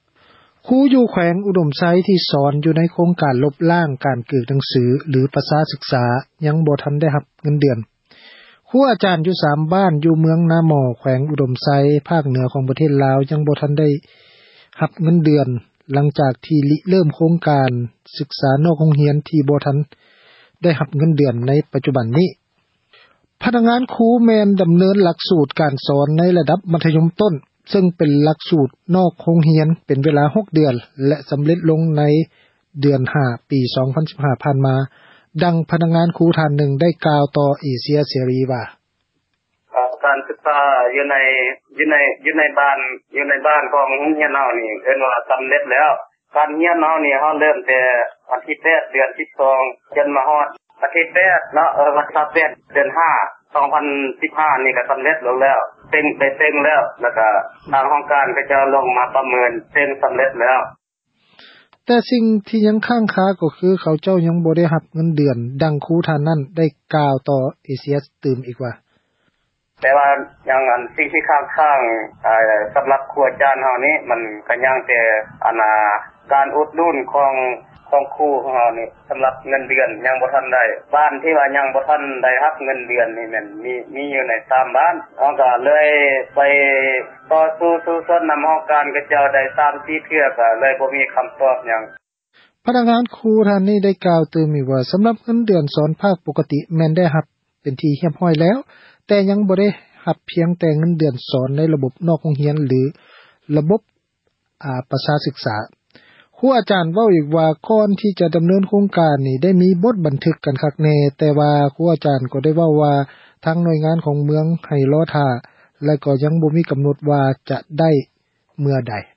ດັ່ງ ພະນັກງານ ຄຣູ ທ່ານນຶ່ງ ໄດ້ກ່າວ ຕໍ່ເອເຊັຽເສຣີ ວ່າ: